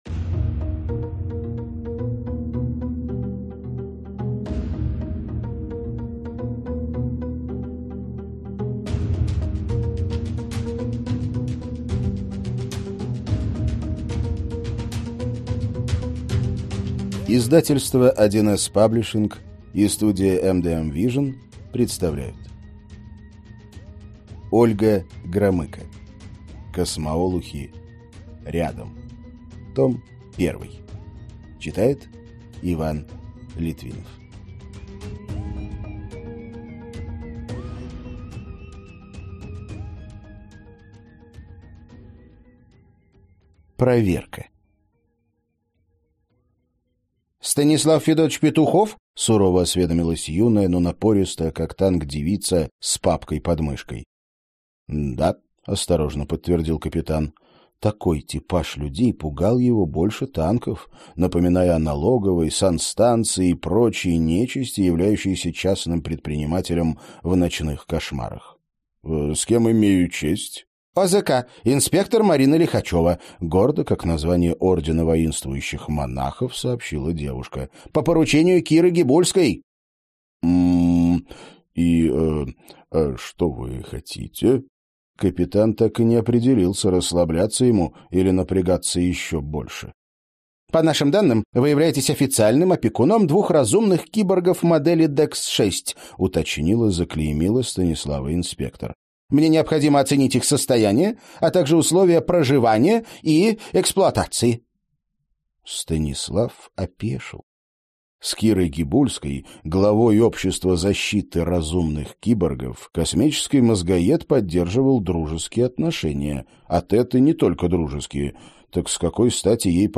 Аудиокнига Космоолухи: рядом.